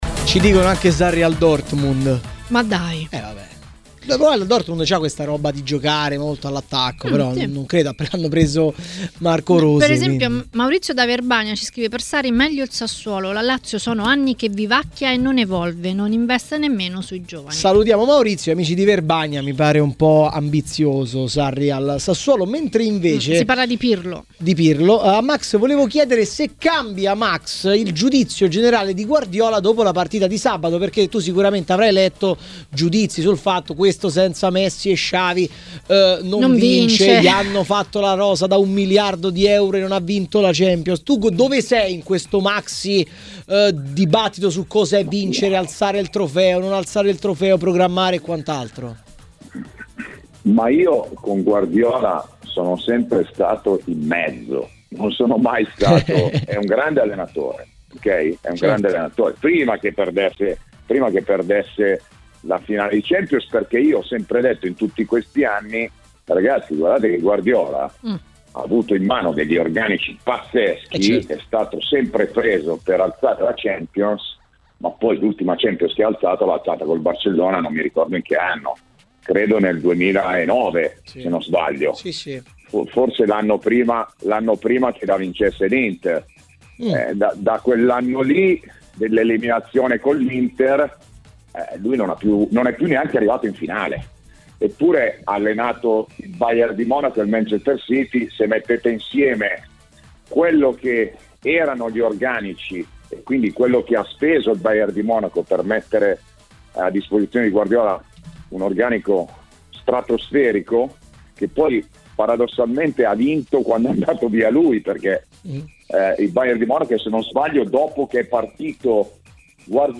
A commentare la recente promozione in Serie A a Maracanà, nel pomeriggio di TMW Radio, è stato Paolo Poggi, responsabile dell'area tecnica del Venezia.